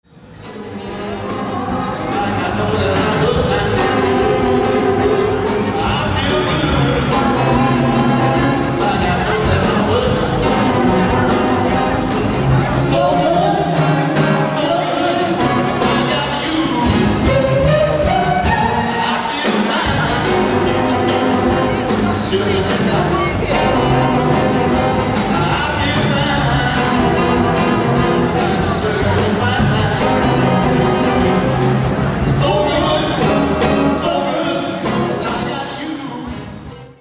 Vocalist
live